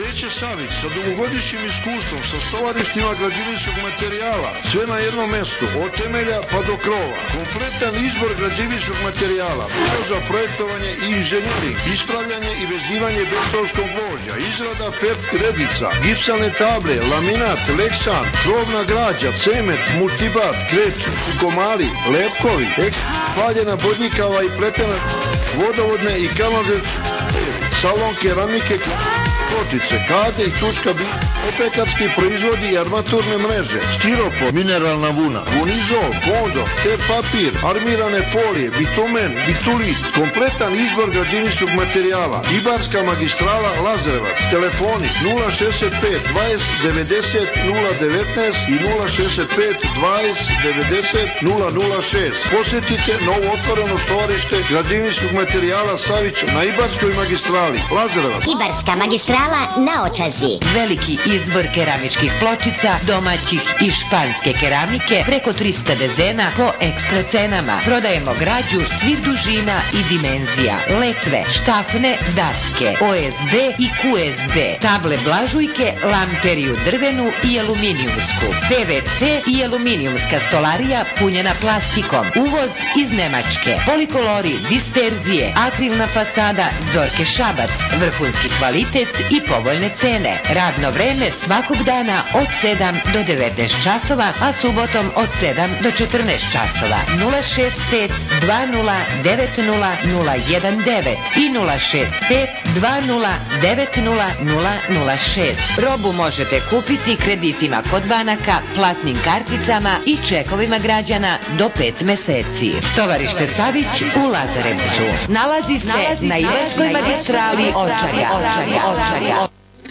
However, I heard also a few pirate stations on FM via ionosphere - from London, Holland and Serbia.
Here are some audio-clips of these pirate stations which I identified last summer on FM (Many of these stations are indentified or tracked of the given telephone-numbers on commercials):
FM-Pirates from Belgrade, Serbia